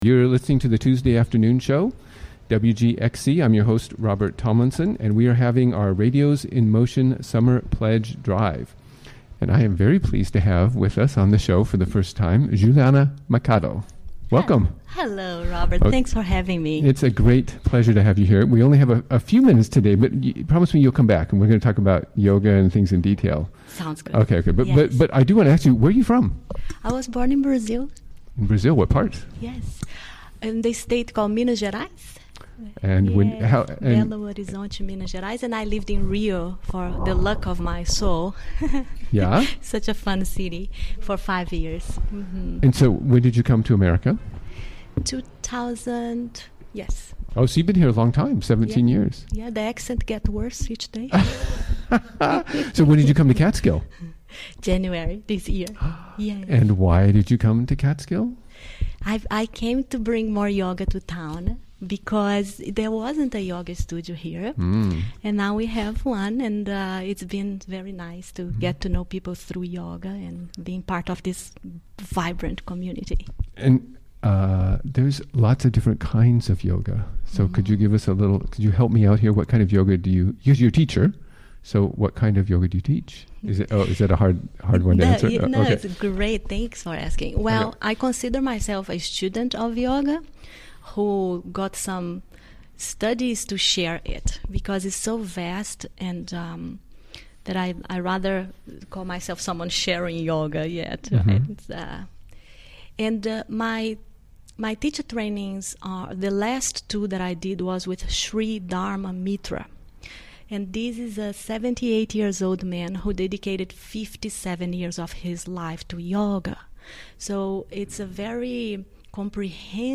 Recorded during the WGXC Afternoon Show of Tuesday, June 13, 2017.